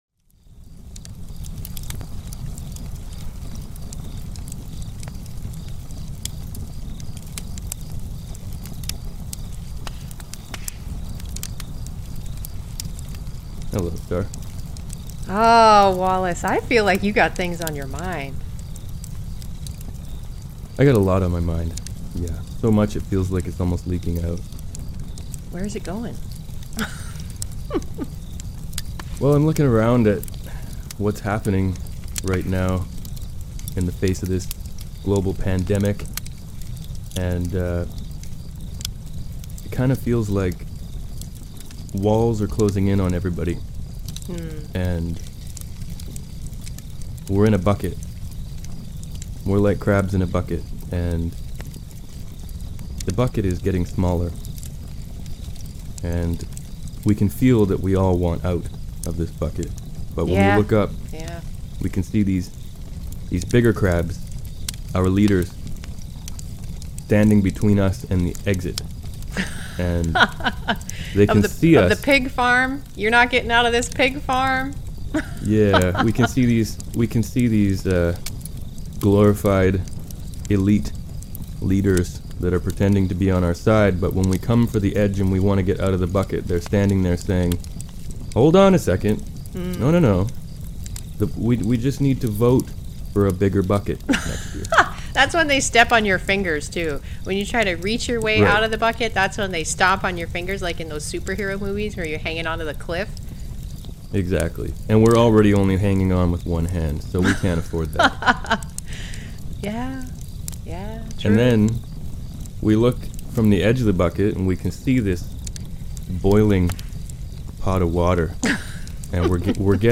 A Conversation around a camp fire.
Friends Discussing a changing world and the events therein – 2021.